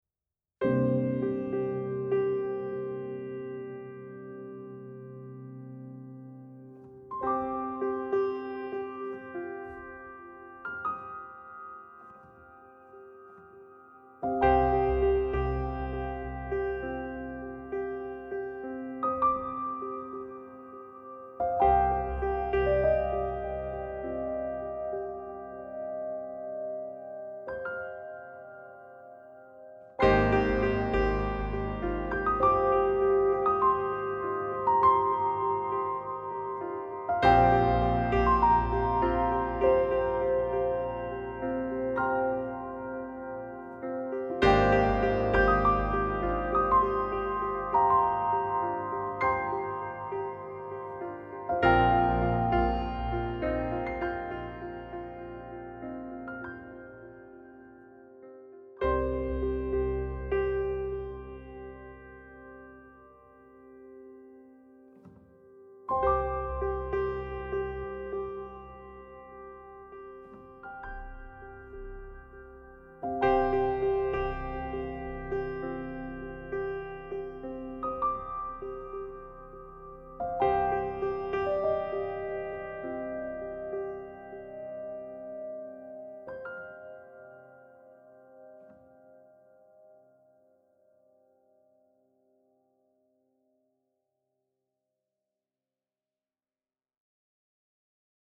for Solo Piano